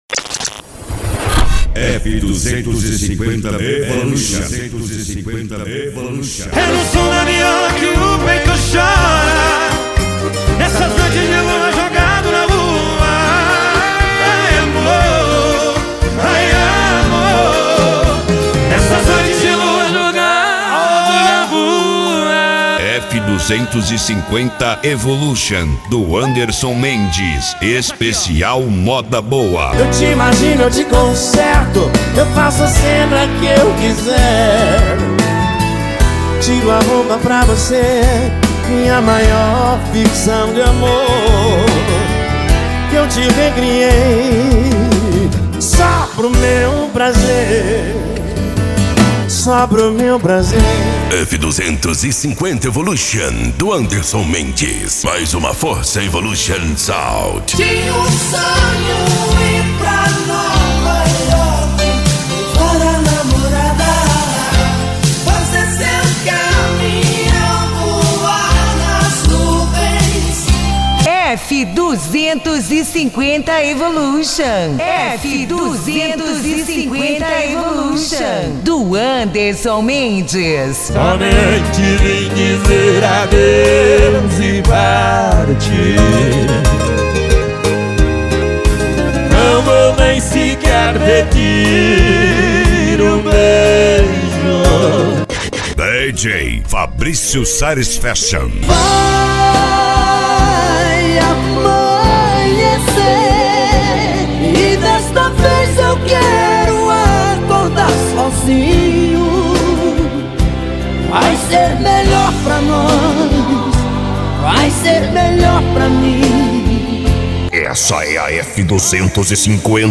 Modao
SERTANEJO